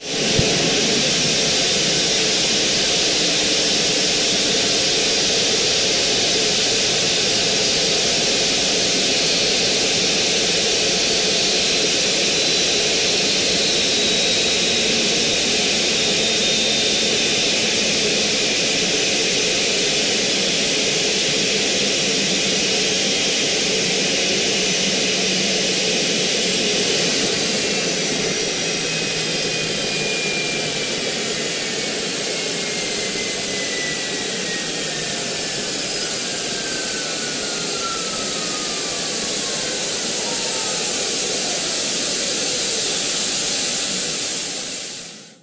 A320-family/Sounds/SASA/CFM56B/external/cfm-shutdown.wav at 5264de9b7f3ab79e4d7ed9f6829ef28ae994f561
cfm-shutdown.wav